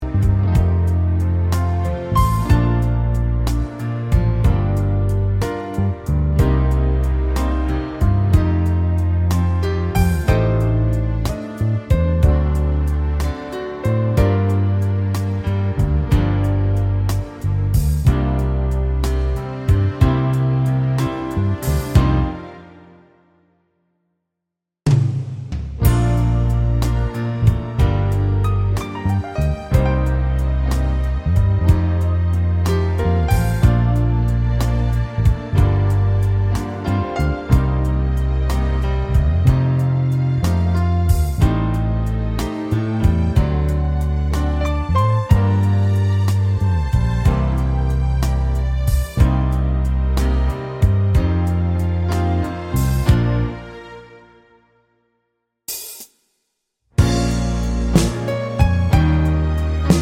With Extra Strings Pop (1970s) 3:23 Buy £1.50